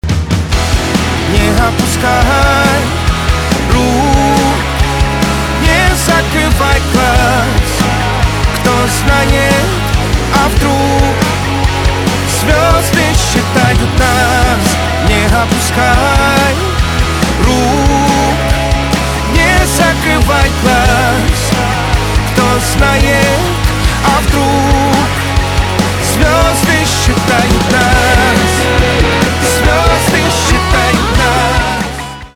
рок
гитара , барабаны , чувственные , позитивные